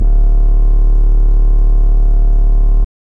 SGLBASS  1-L.wav